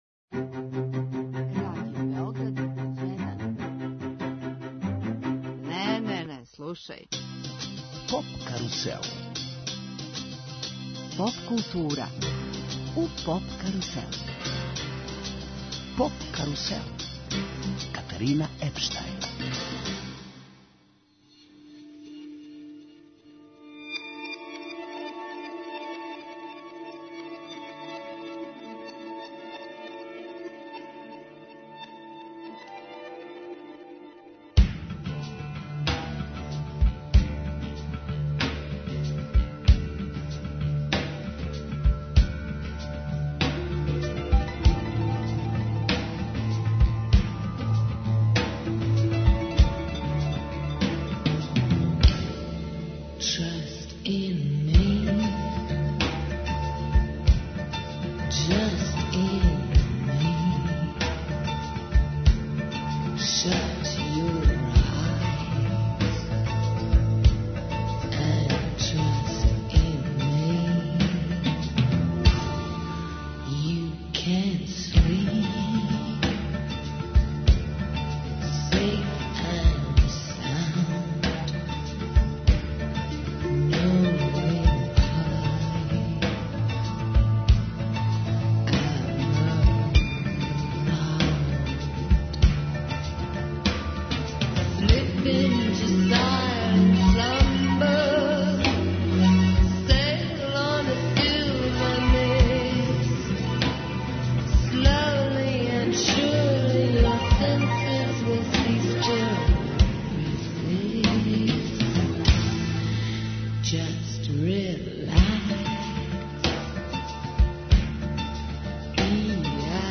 Гости су нам алтернативни вокално-инструментални састав Хоркестар.